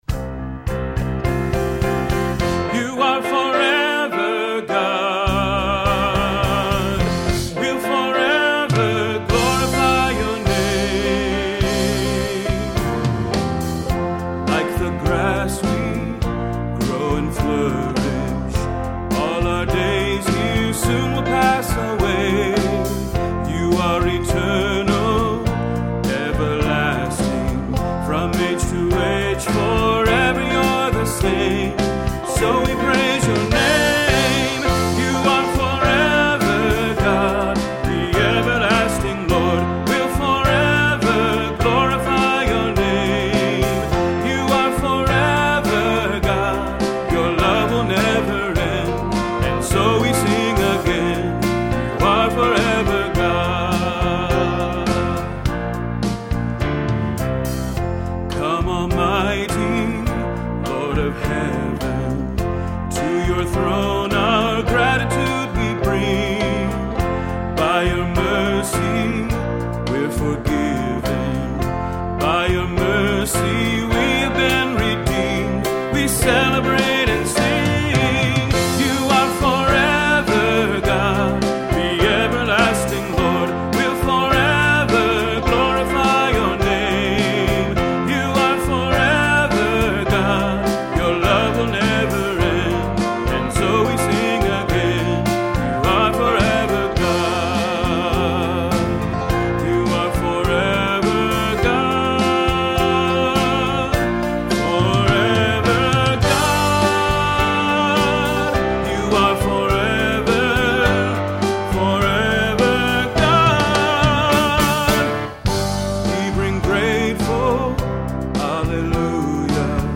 Voicing: Vocal Collection